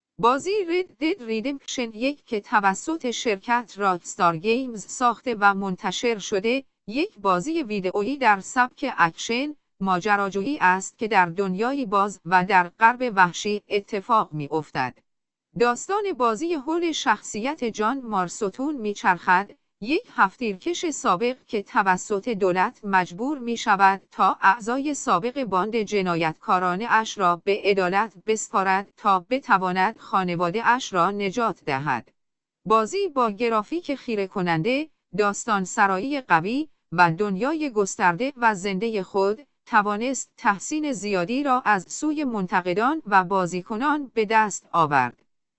صوت-خلاصه-محتوا-Red-Dead-Redemption-1.ogg